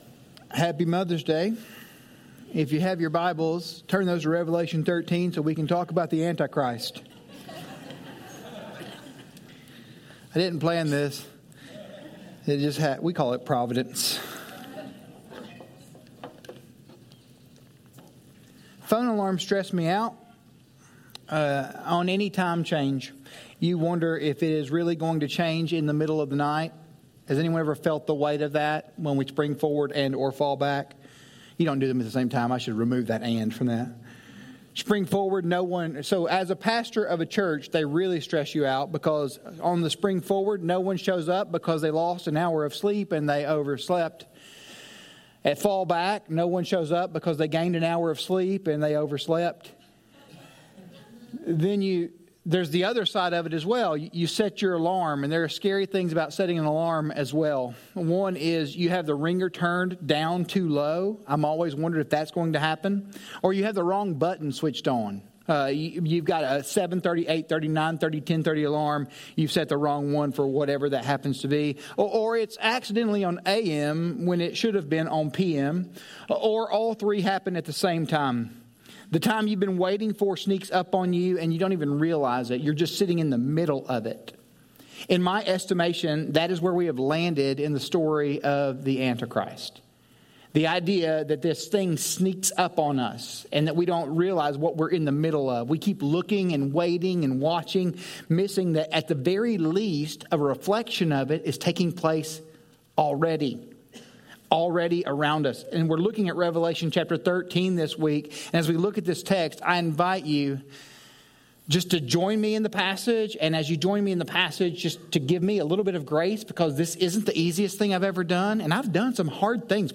Sermon Teaching Podcasts